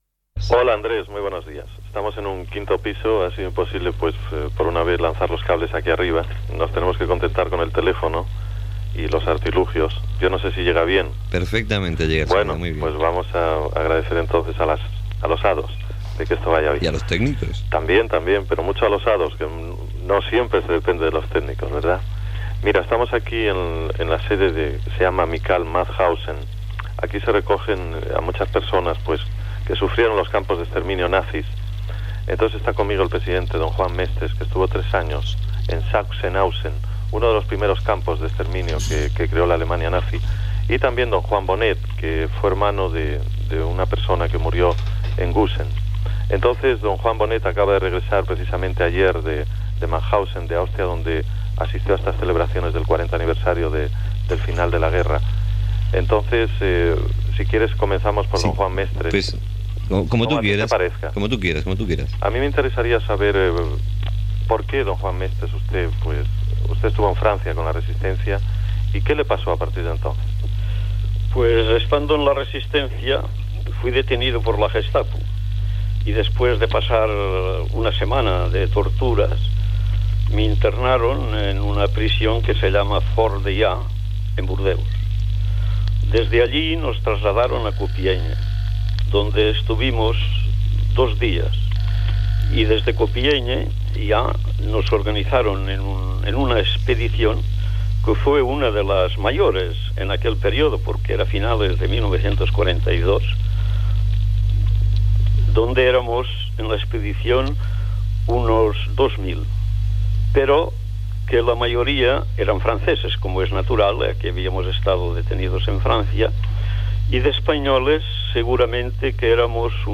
Entrevista
feta a Mauthausen (Alemanya). S'hi parla del camp de concentració i extermini Nazi i de les seves vivències i records